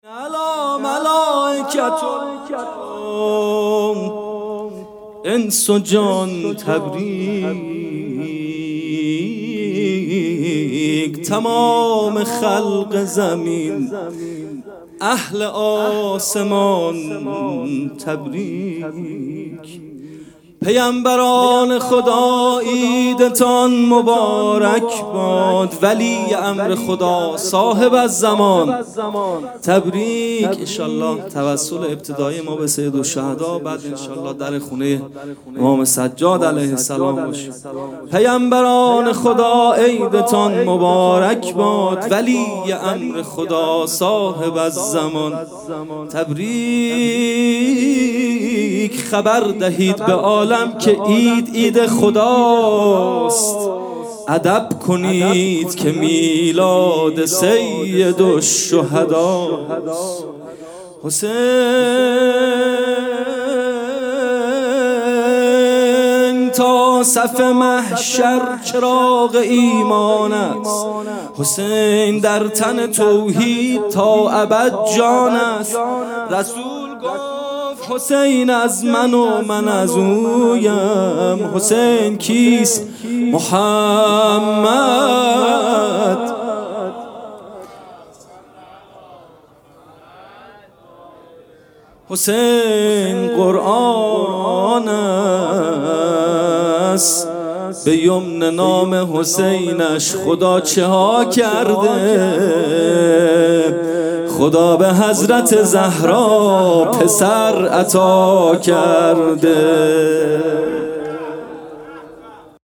جلسه هفتگی
music-icon سرود